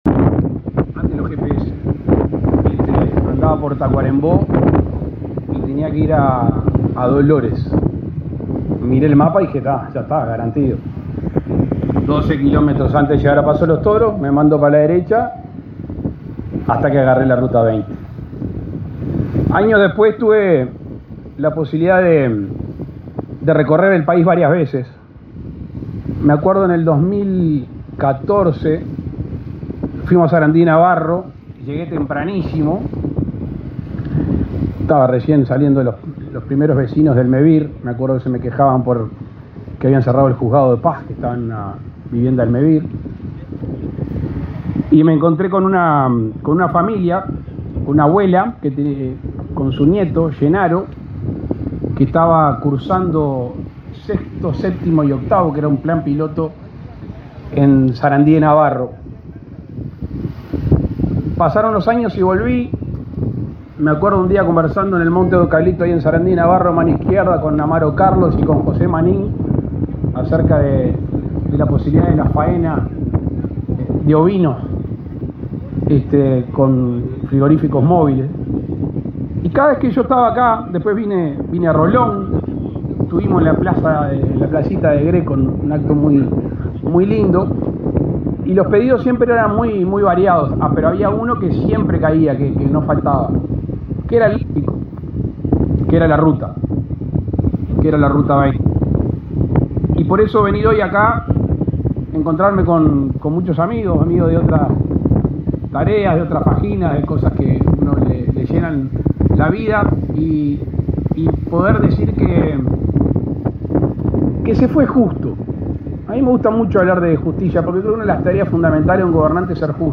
Palabras del presidente de la República, Luis Lacalle Pou
Este 13 de junio fueron inauguradas las obras en la ruta 20. El presidente de la República, Luis Lacalle Pou, participó del evento.